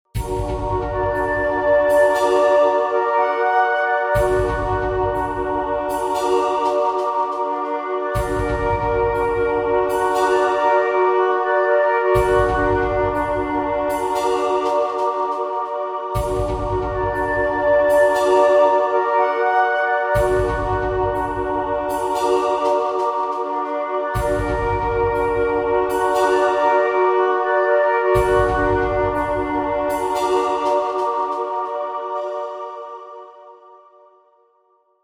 Groove in 3edo
3edo_groove.mp3